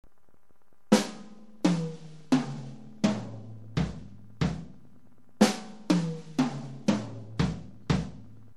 DRUM SET